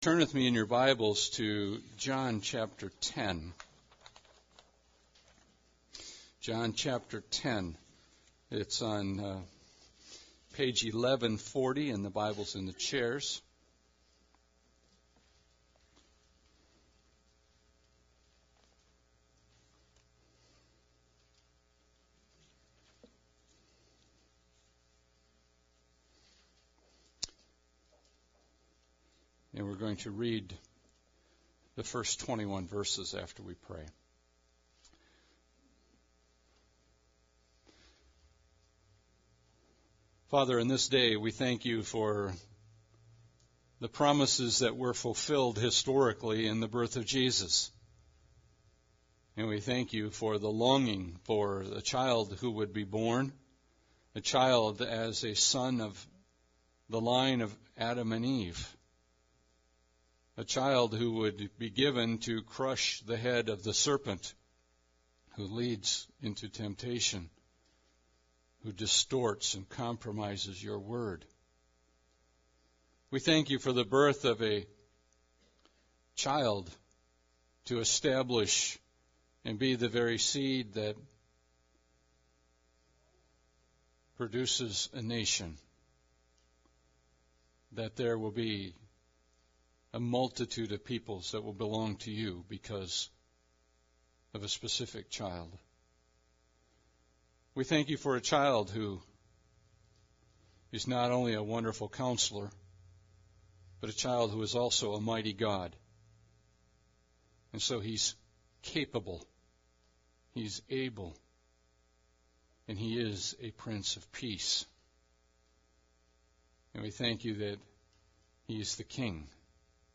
John 10 Service Type: Sunday Service Bible Text